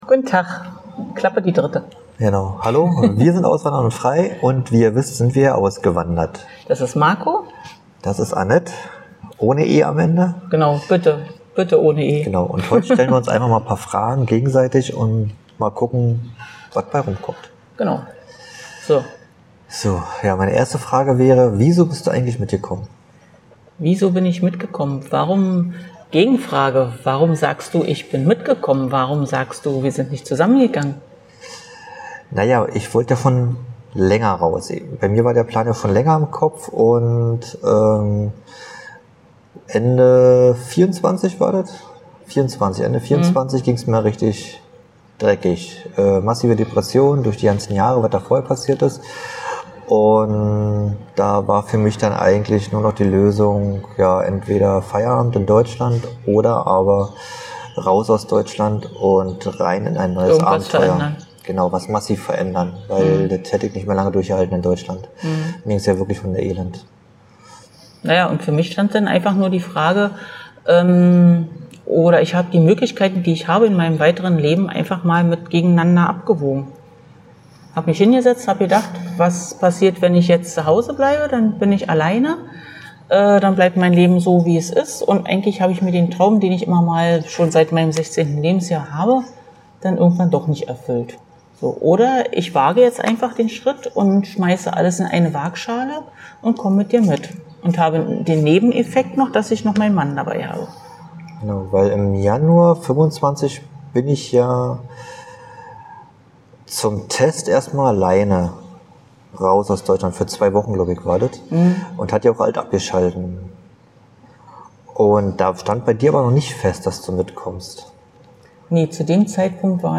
In dieser Folge setzen wir uns zusammen und stellen uns gegenseitig die Fragen, die viele von euch brennend interessieren – ehrlich, direkt und ohne Skript.